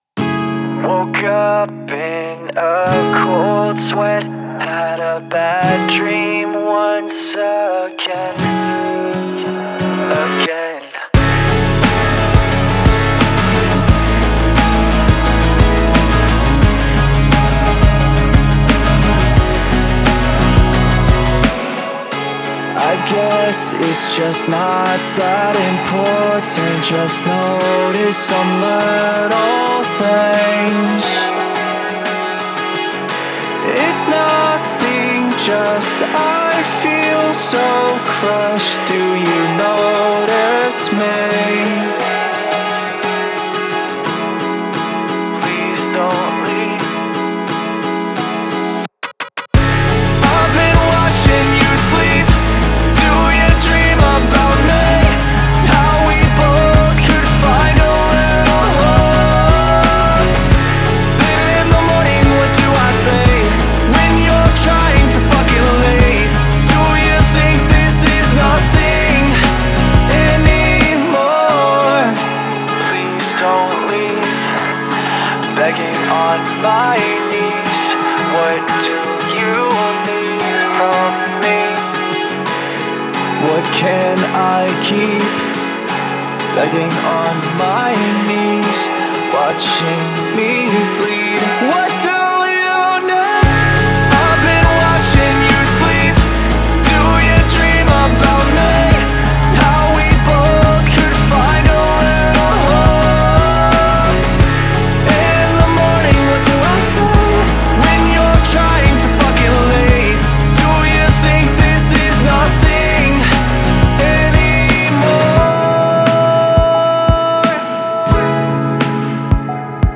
is in the key of C sharp minor